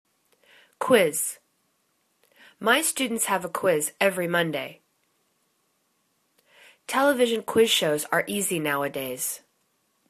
quiz     /kwiz/    n